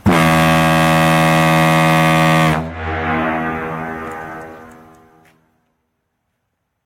ShipHorn_02.mp3